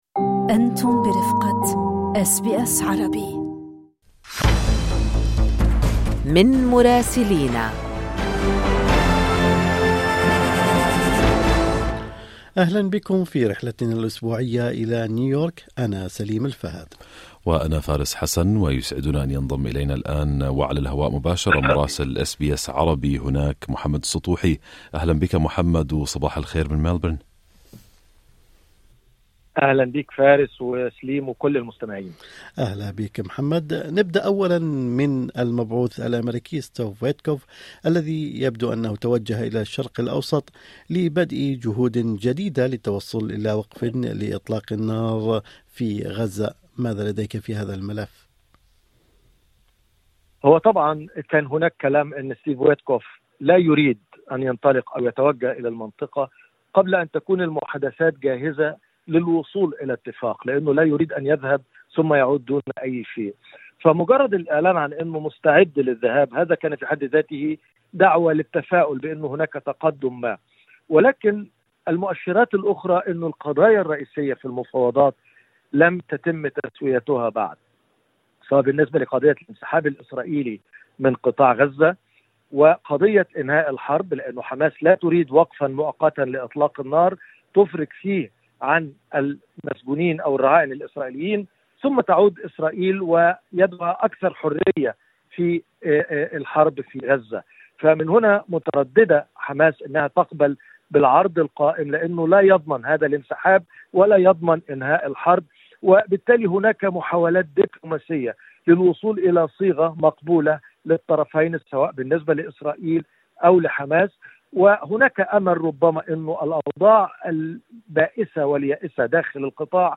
المبعوث الامريكي يتوجه إلى الشرق الاوسط لمواصلة الجهود حول غزة في تقرير مراسلنا في الولايات المتحدة